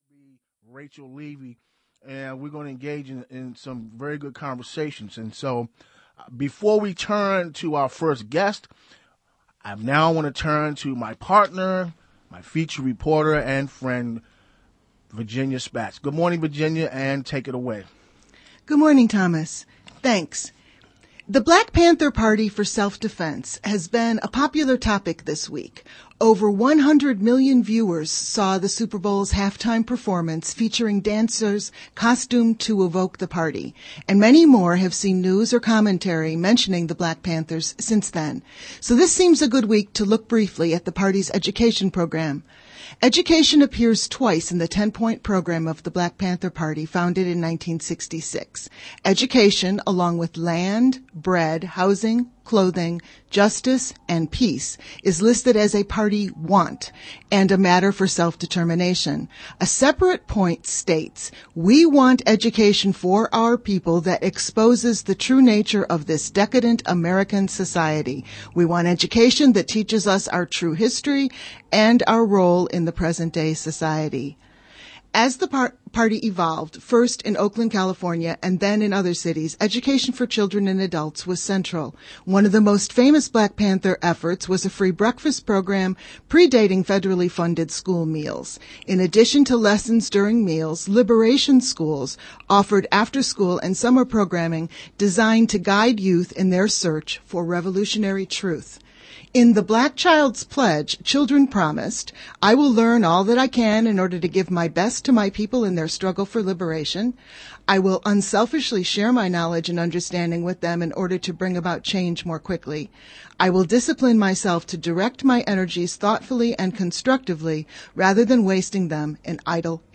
Feature Report, Education Town Hall, 2-11-16
The Education Town Hall broadcasts from Historic Anacostia